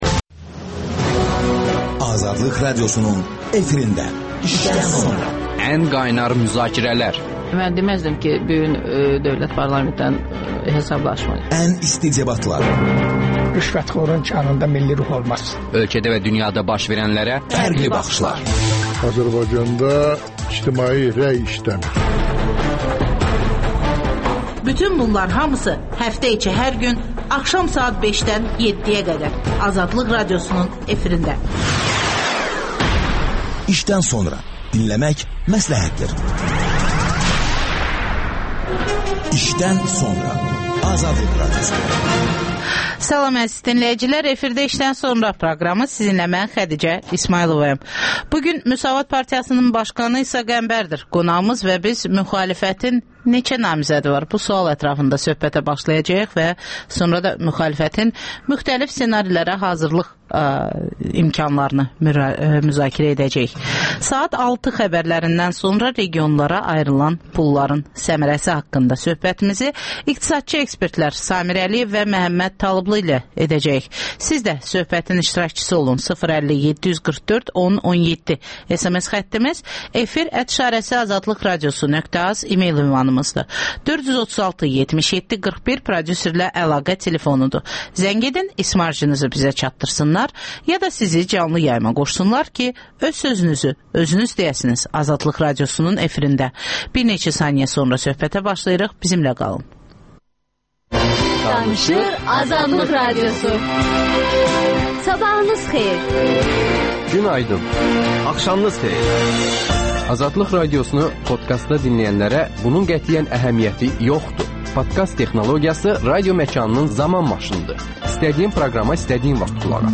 Müsavat Partiyasının başqanı İsa Qəmbər ilə söhbət.